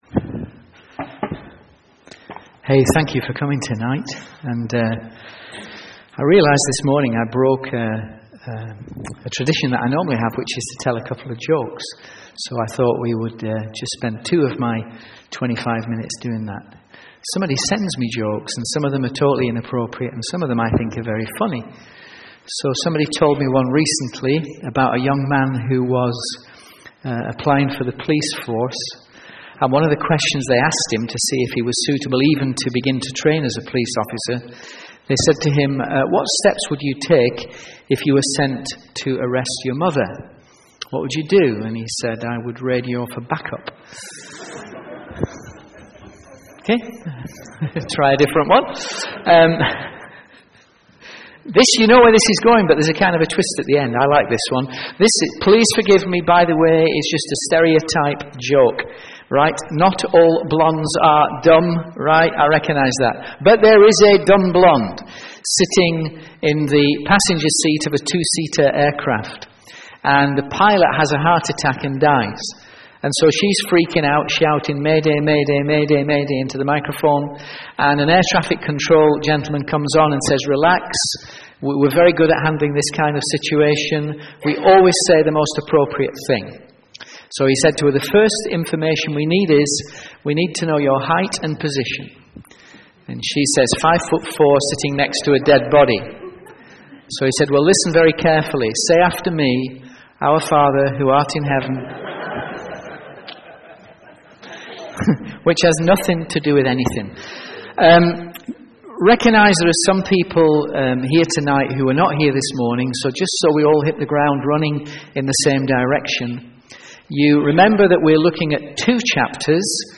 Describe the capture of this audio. From Series: "Sunday Evening"